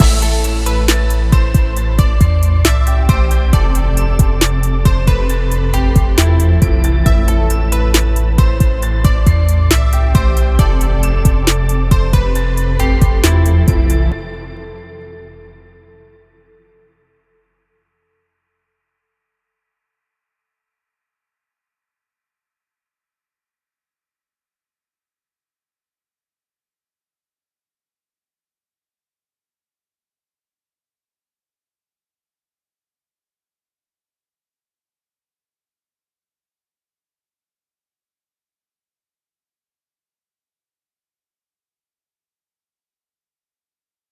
hi leute, welchen audio clip findet ihr vom sound besser, mit fokus auf den low end? danke falls jemand zeit hat zu horchen Anhänge TEST B.wav TEST B.wav 12,1 MB TEST A.wav TEST A.wav 12,1 MB Zuletzt bearbeitet: 12.08.23